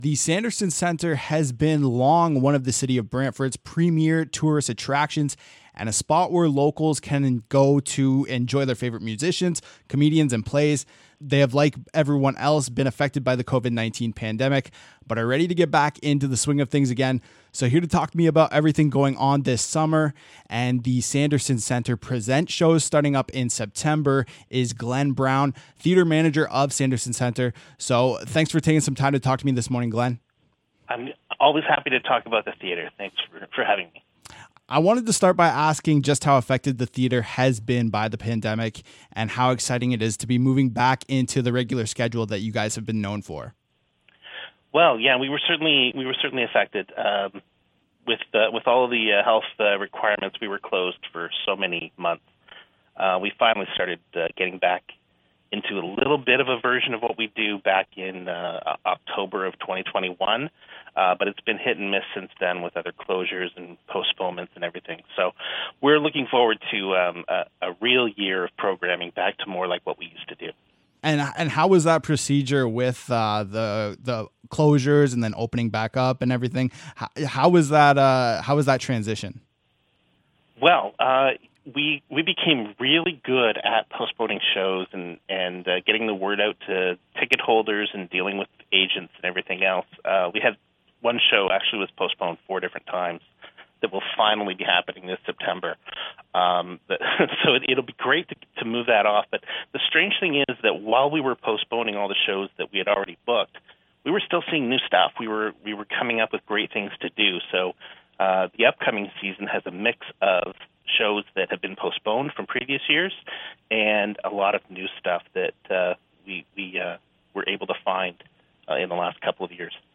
spoke with CJKS about what visitors and audiences can expect for the upcoming season.